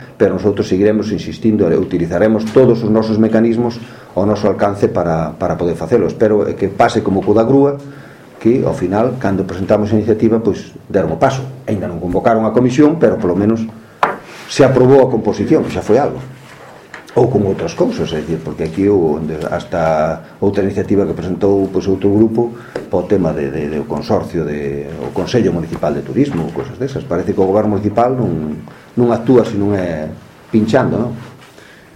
O voceiro do Grupo Municipal do Partido Popular no Concello de Lugo, Jaime Castiñeira, anunciou esta mañá en rolda de prensa un ultimátum ao goberno local para que “nun prazo máximo de dez días” convoque a Comisión de Planeamento.